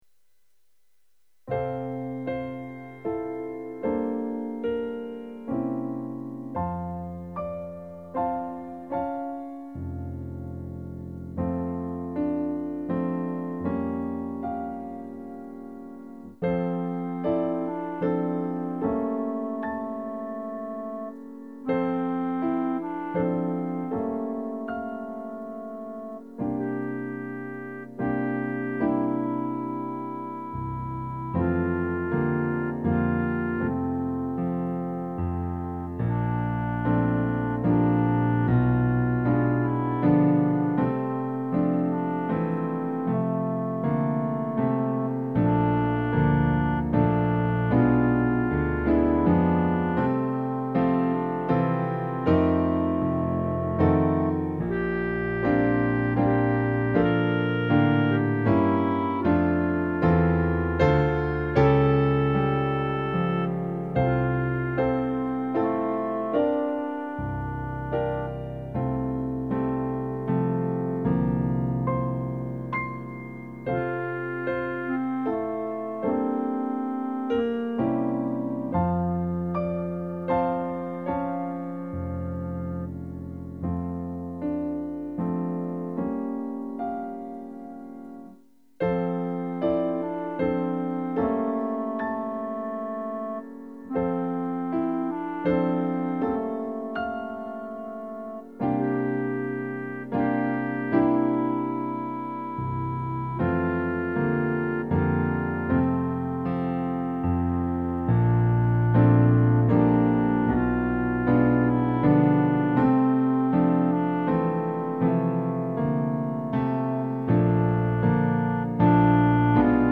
Voicing/Instrumentation: Primary Children/Primary Solo We also have other 116 arrangements of " Silent Night ".
Vocal Solo Medium Voice/Low Voice
Lullabies